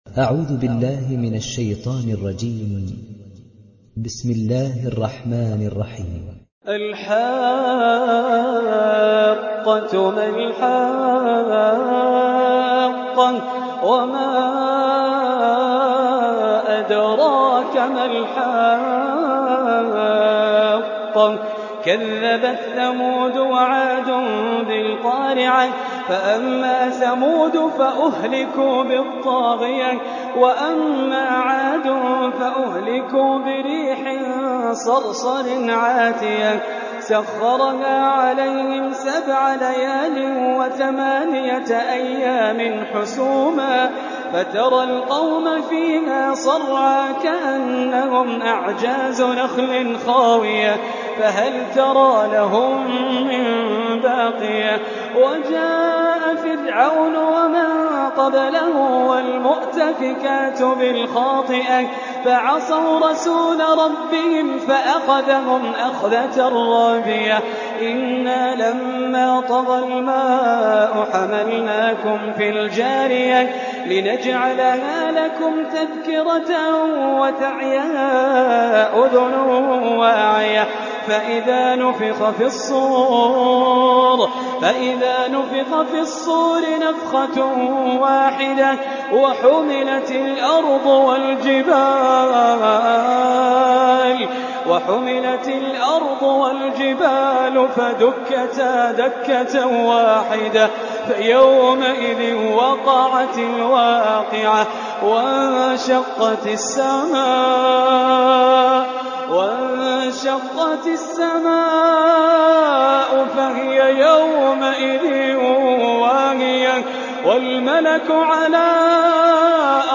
উপন্যাস Hafs থেকে Asim